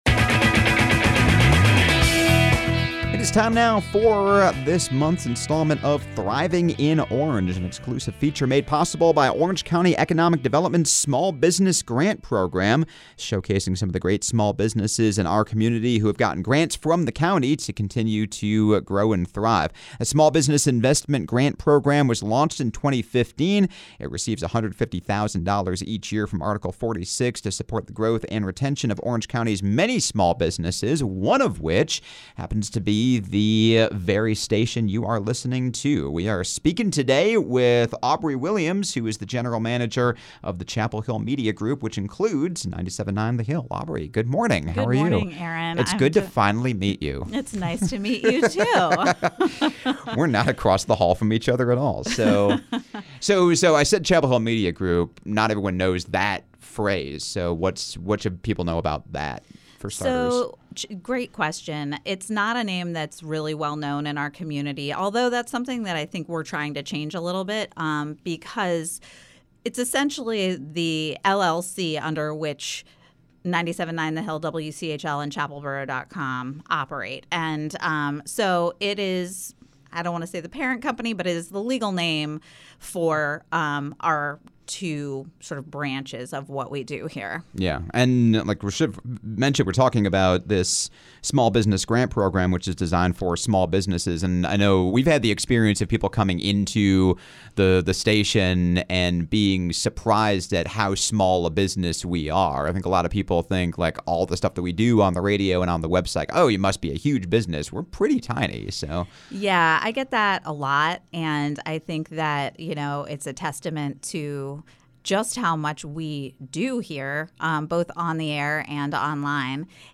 A monthly segment presented by Orange County Economic Development, “Thriving in Orange” features conversations with local business owners about what it’s like to live and work in Orange County, especially in light of the county’s small business grant program which launched in 2015 and has helped small businesses and small business owners with well over $100,000 in grants each year!